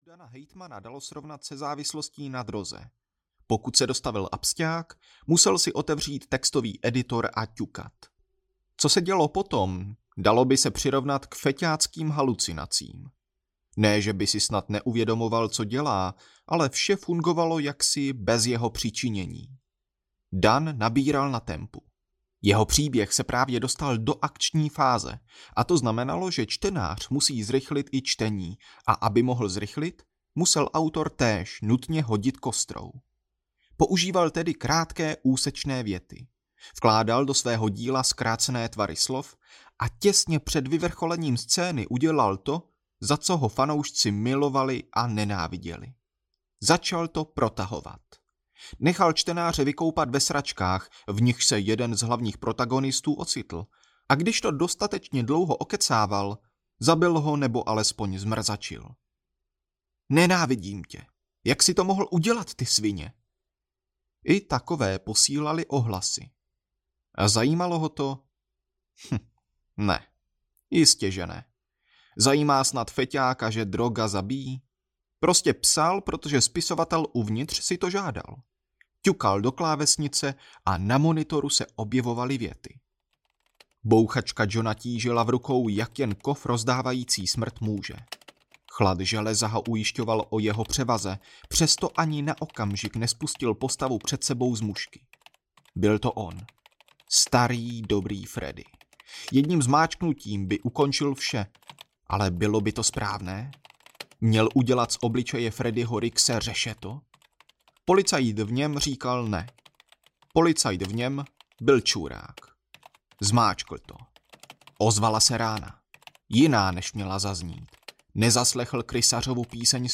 Smrt bývá nehezká audiokniha
Ukázka z knihy
smrt-byva-nehezka-audiokniha